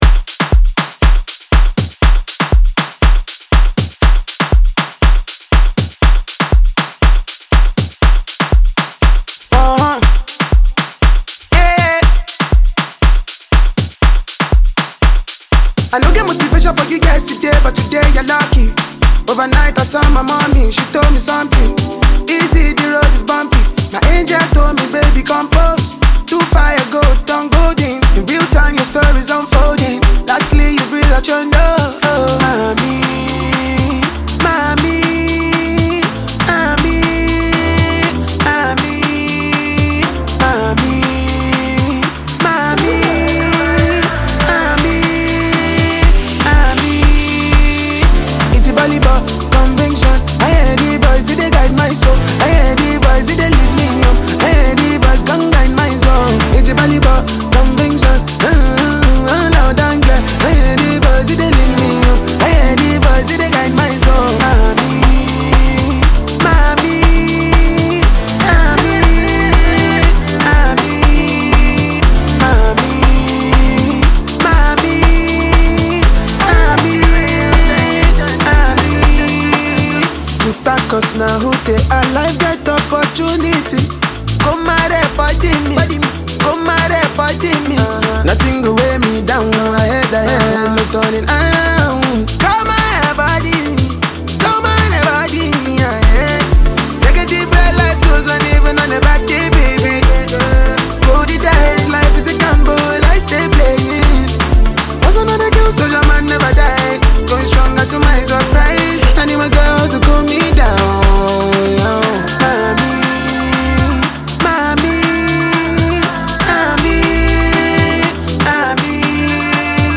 Nigerian singer, songwriter, and performer
infectious rhythm
catchy hooks